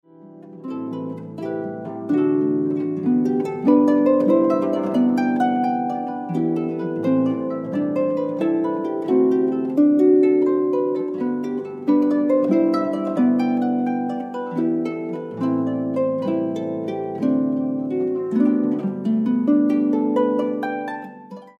This CD is a wonderful collection of harp music including